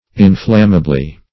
Search Result for " inflammably" : The Collaborative International Dictionary of English v.0.48: Inflammably \In*flam"ma*bly\ ([i^]n*fl[a^]m"m[.a]*bl[y^]), adv.
inflammably.mp3